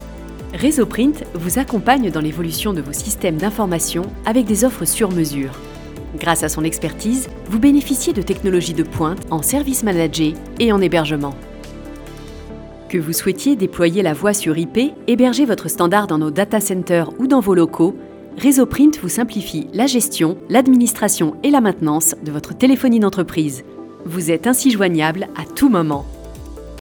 Institutionnel voix chaleureuse voix élégante Institutionnel Catégories / Types de Voix Extrait : Votre navigateur ne gère pas l'élément video .